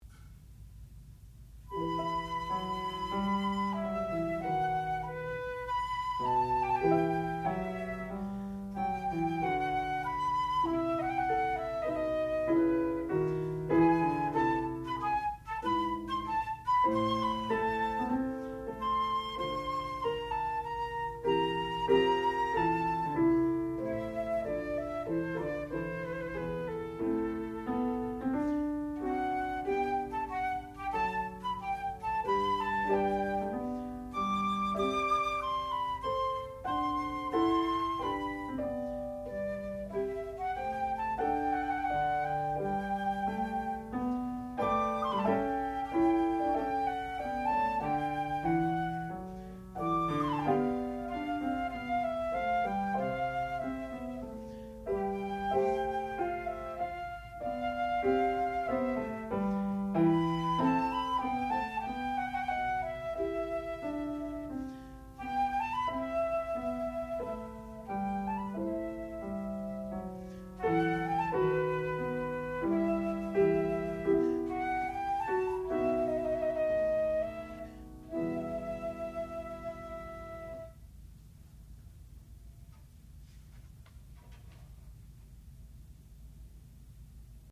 sound recording-musical
classical music
flute
harpsichord
Master's Recital